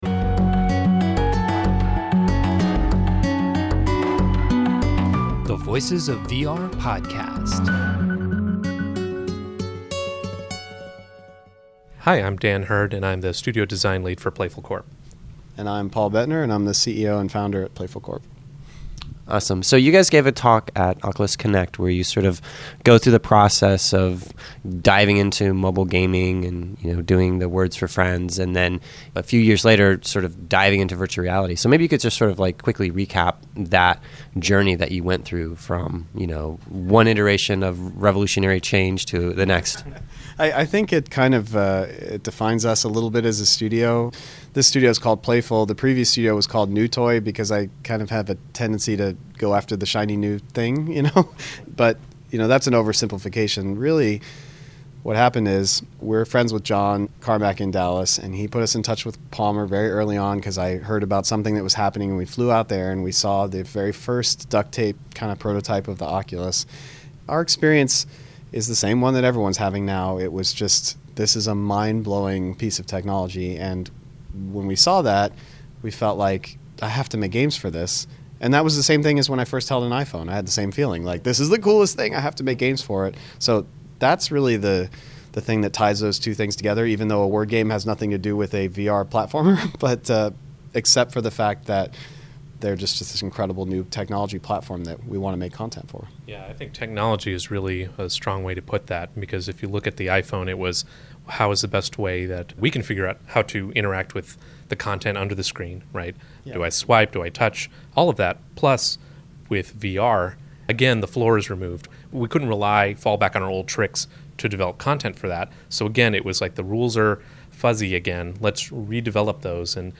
I had a chance to play Lucky’s tale for the first time at GDC, and I have to say that I was blown away. I had a half hour for the interview, and I had the best intentions to get a brief feel for the game and then do the interview.